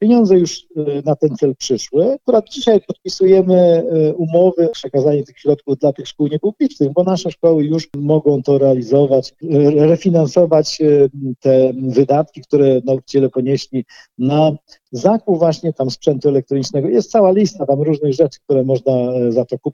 Mówi starosta powiatu ełckiego Marek Chojnowski.